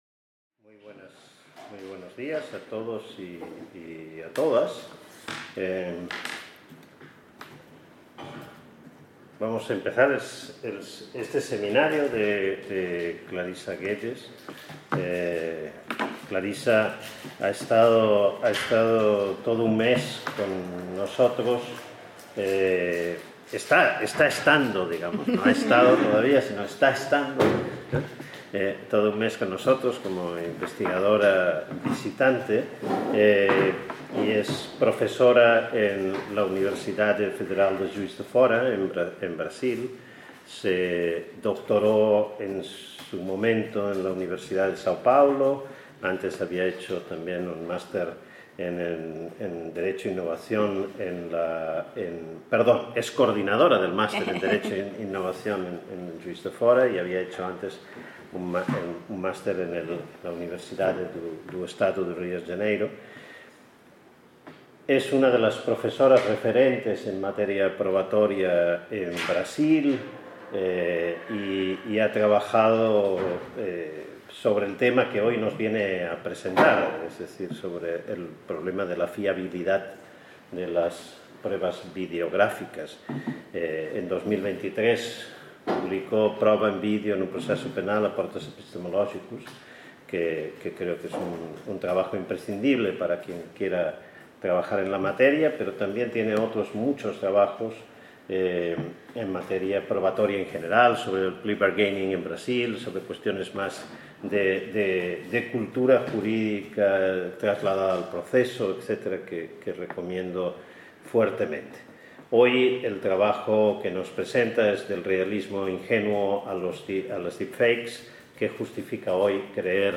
Seminar organized by the Research Group on Philosophy of Law